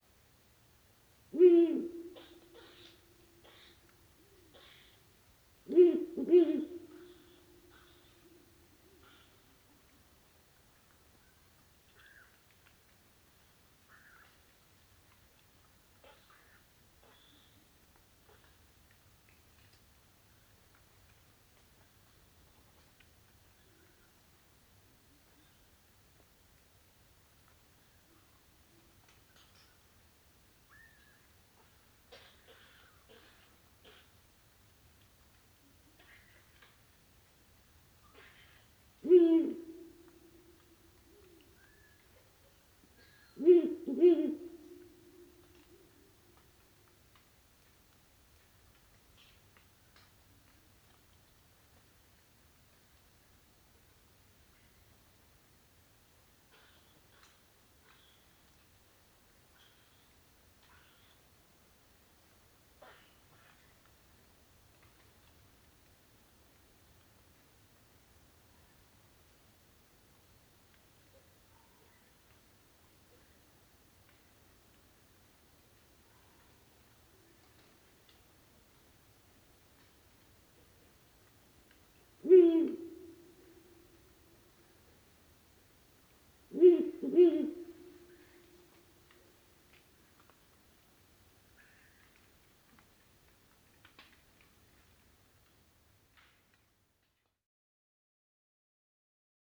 The male in CD4-04 is not alone. In the distance there are at least two others.
The many harsh squeaks belong to Edible Dormice Glis glis.
Hooting of three males.
4-04-Ural-Owl-Compound-Hooting-Of-Three-Males-From-Slovenia.wav